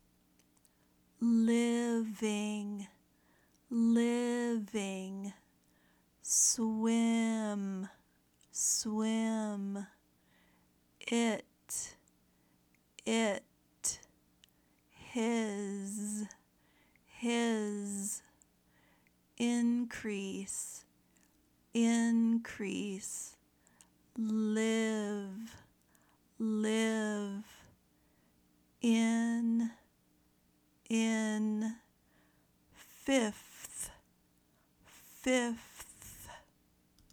Learn the Short "I" Sound
Here are words with the short i sound from today’s verses.
Practice the Short “I” Sound